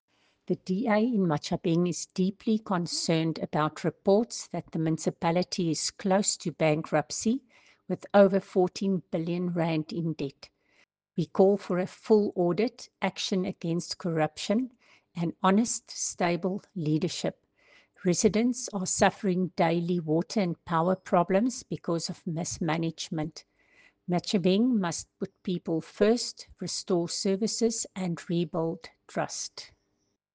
English and Afrikaans soundbites by Cllr Coreen Malherbe and Sesotho soundbite by Cllr Kabelo Moreeng.